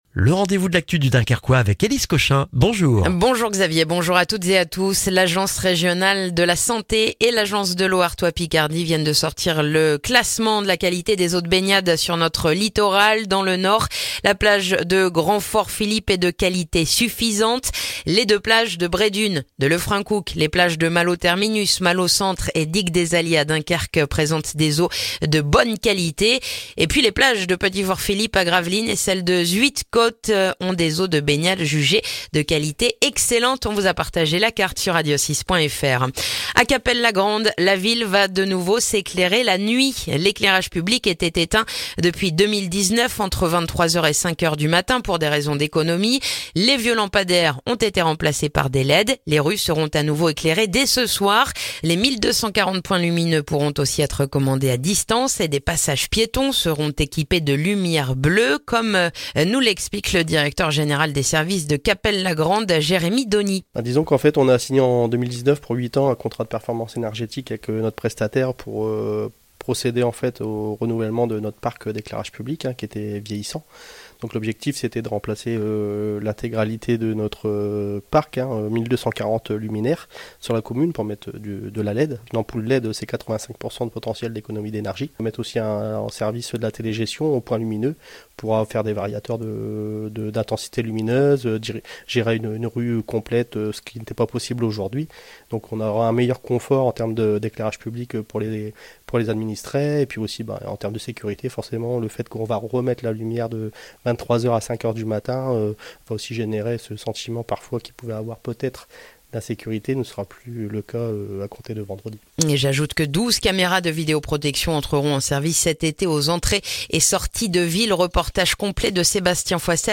Le journal du vendredi 21 juin dans le dunkerquois